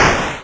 balloon_pop3.wav